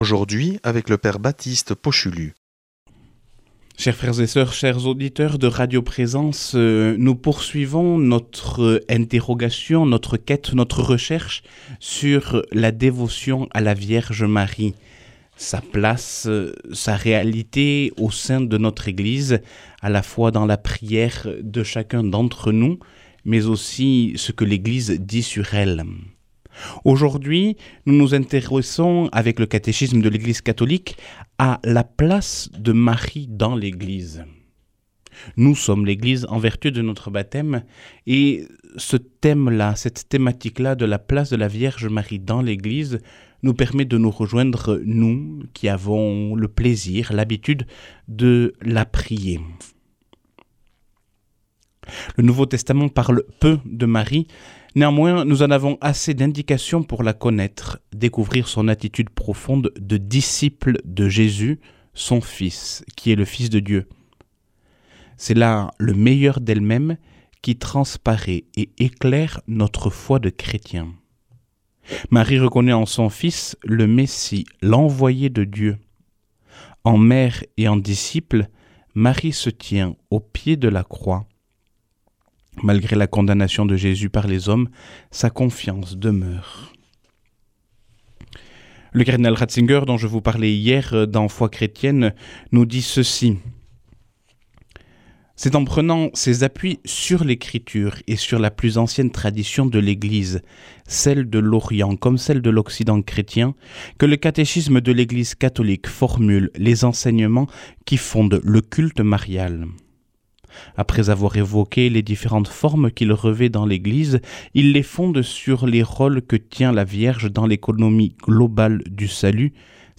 mardi 12 novembre 2024 Enseignement Marial Durée 10 min
Une émission présentée par